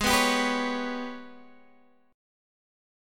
AbmM7bb5 chord